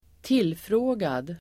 Ladda ner uttalet
Uttal: [²t'il:frå:gad]